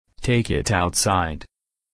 Вы можете переворачивать карточки по одной или все вместе, повторяйте за диктором фразу на английском: Stop!